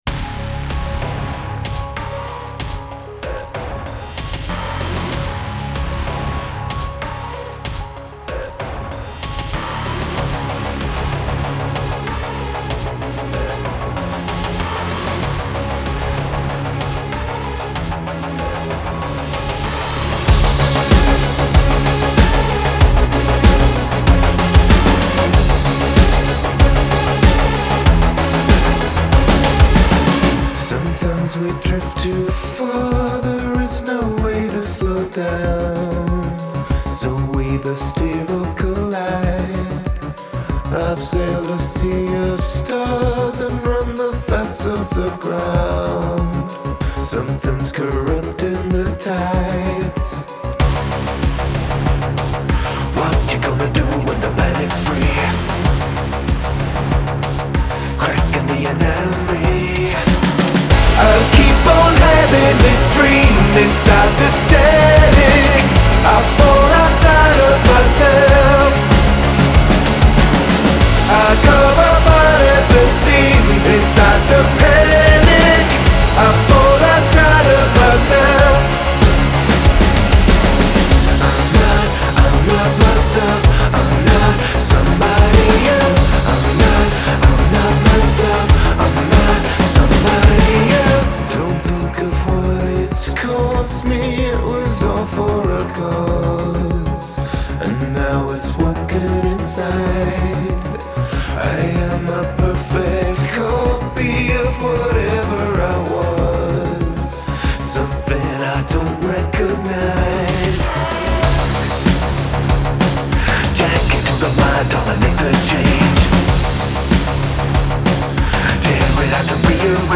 Genre : Alternative, Indie